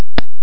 Tymp Sound Effect
tymp.mp3